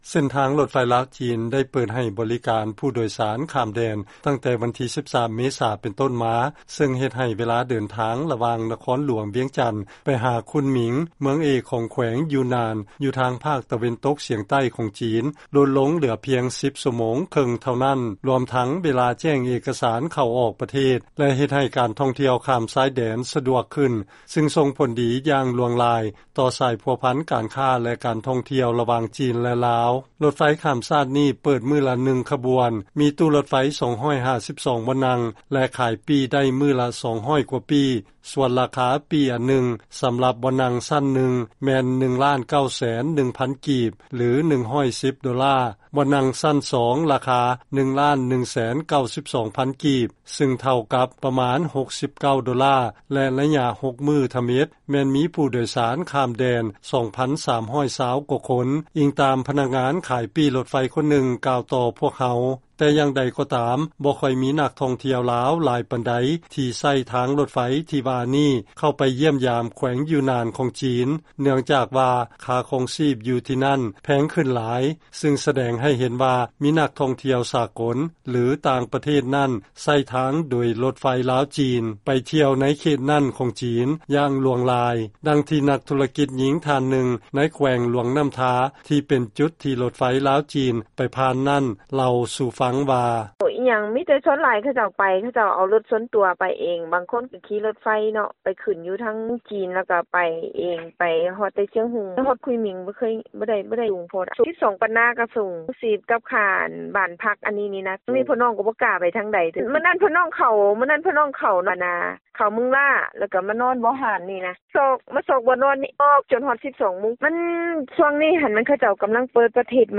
ຟັງລາຍງານ ການເປີດບໍລິການ ລົດໄຟລາວ-ຈີນ ແກ່ຜູ້ໂດຍສານຂ້າມແດນ ເລີ້ມຕົ້ນແລ້ວ ແຕ່ບໍ່ຄ່ອຍມີນັກທ່ອງທ່ຽວລາວ ຫຼາຍປານໃດໃຊ້ ມີແຕ່ຊາວຕ່າງຊາດ